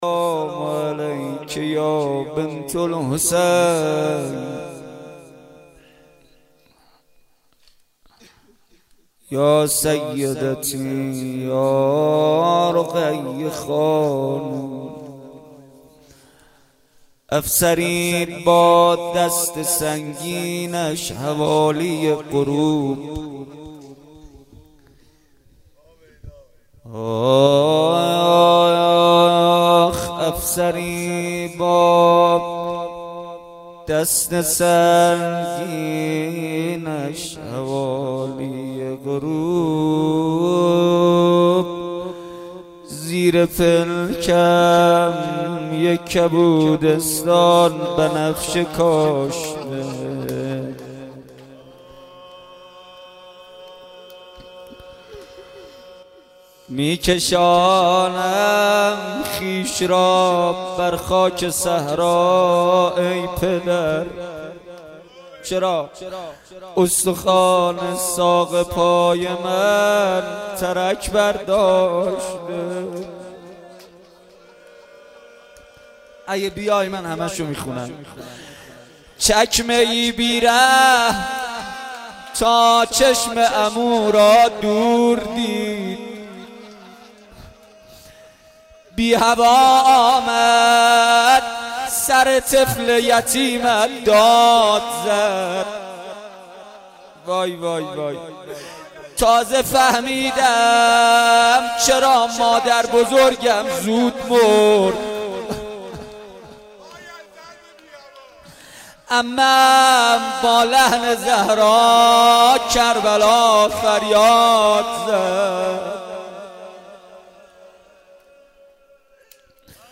شب سوم رمضان93